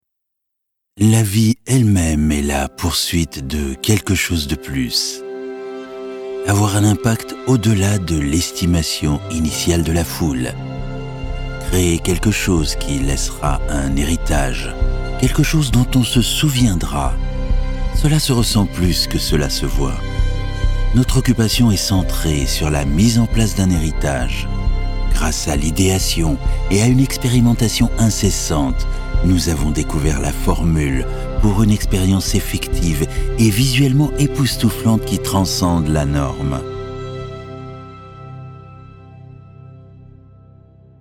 Male
Assured, Character, Confident, Corporate, Engaging, Natural, Reassuring, Smooth, Warm, Versatile, Approachable, Upbeat
French (Native), English (French accent).
Microphone: Neuman M147 U87 // Sennheiser MKH 416 // L22 // Lewitt 640s